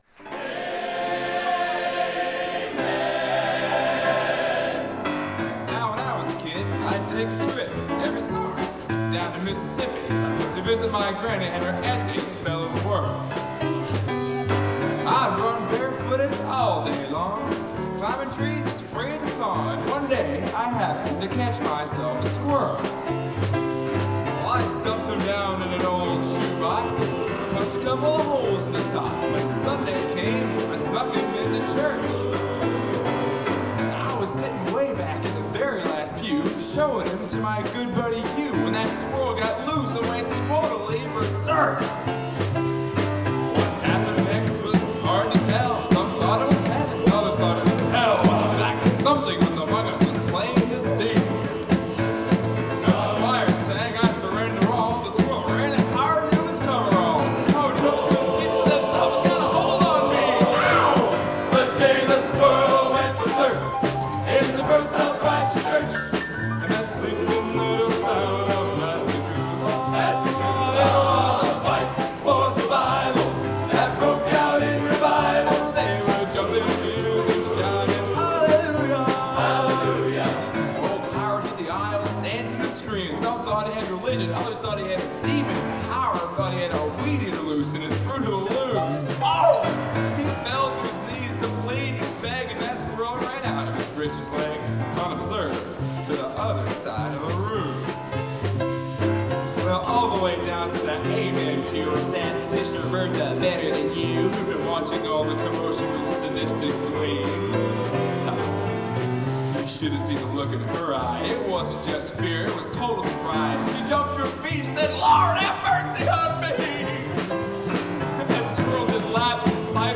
Genre: Country/Western Humor/Parody | Type: Solo